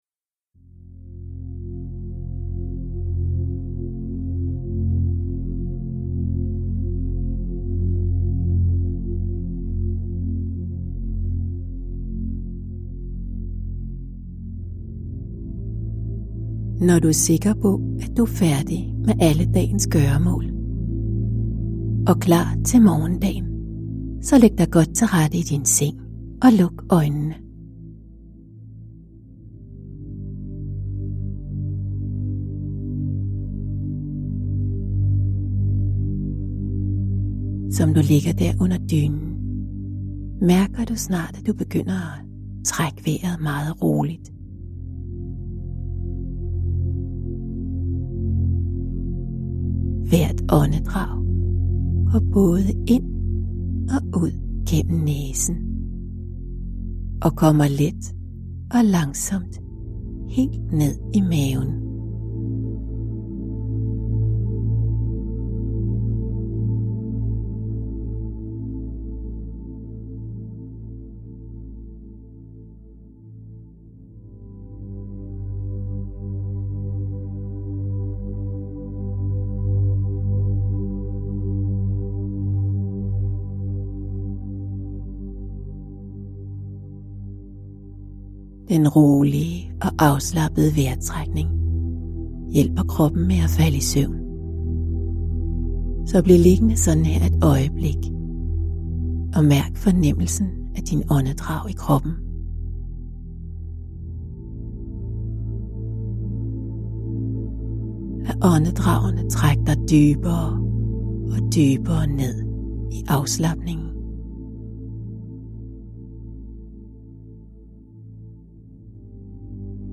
Ljudbok
De danske udgaver er indlæst af professionelle speakere.